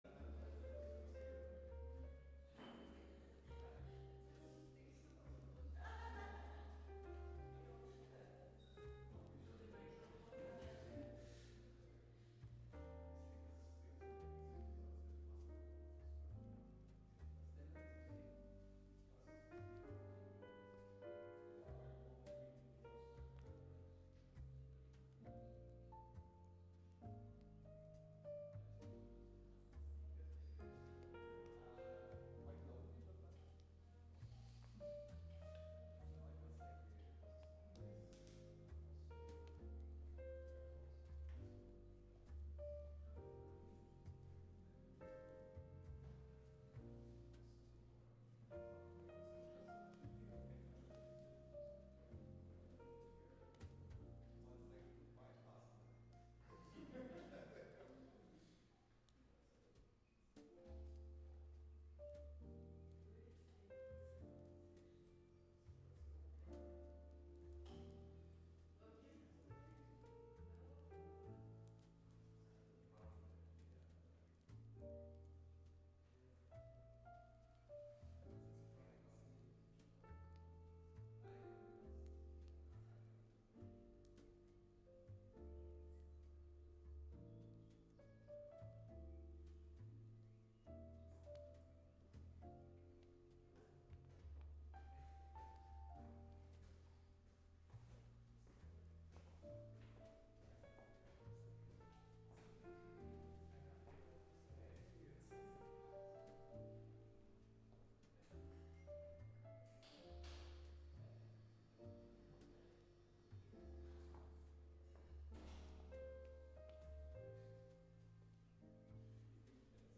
live sets